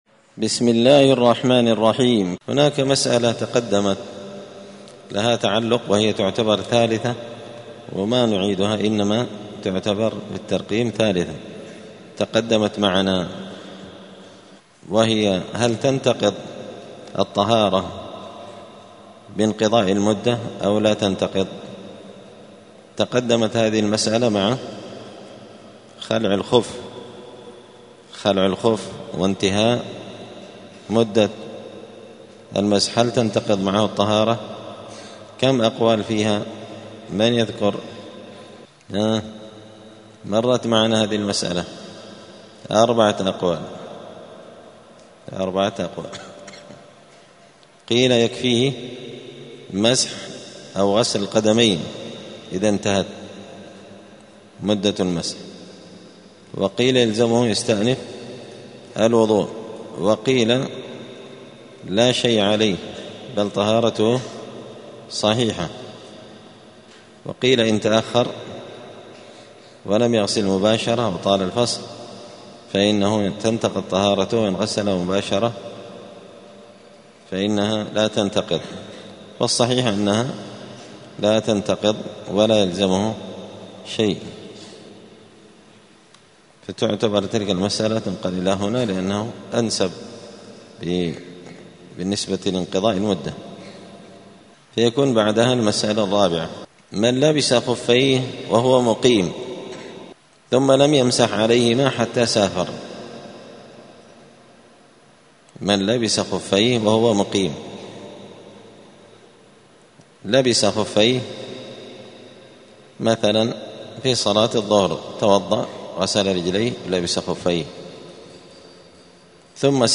دار الحديث السلفية بمسجد الفرقان قشن المهرة اليمن
*الدرس الخامس والأربعون [45] {باب صفة الوضوء مسح الخفين للمسافر}*